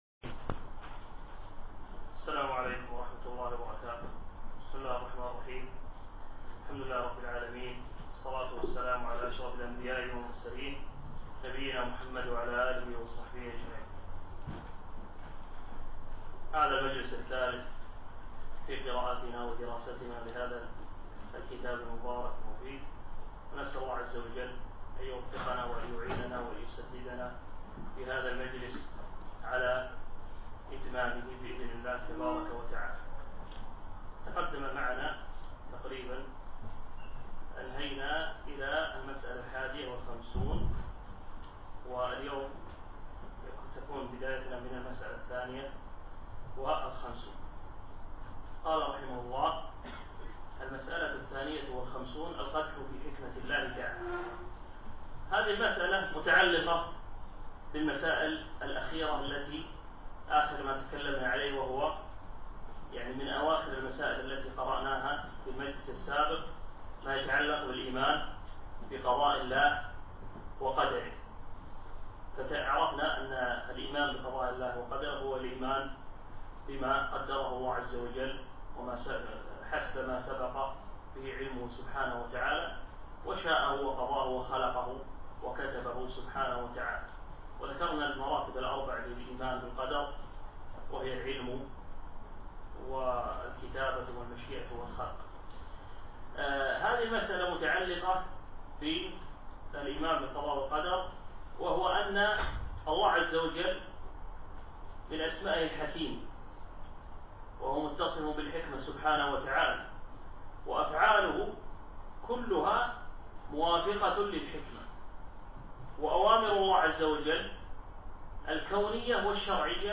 أقيمت هذه الدورة بمركز القصر نساء مسائي